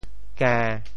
Details of the phonetic ‘gên1’ in region TeoThew
IPA [kε̃]